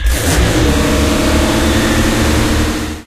truck_goes.ogg